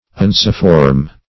Unciform \Un"ci*form\ ([u^]n"s[i^]*f[^o]rm), a. [L. uncus a hook